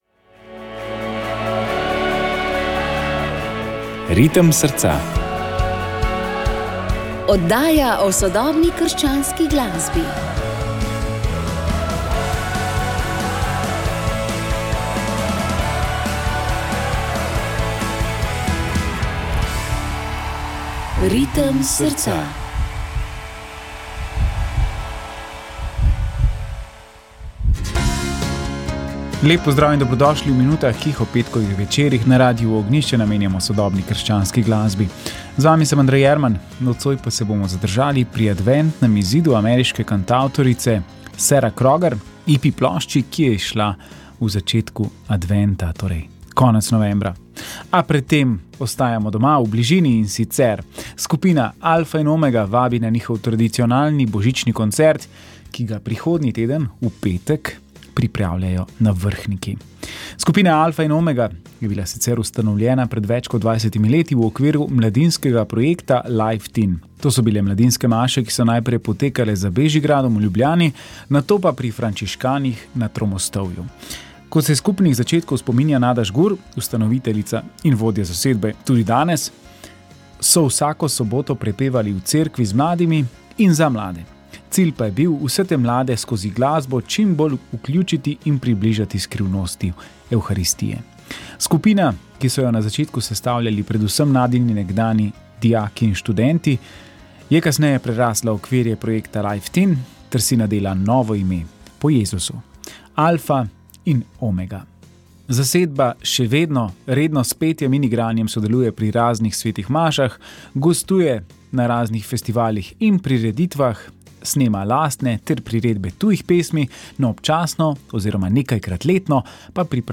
Slišali bomo vtise mladih.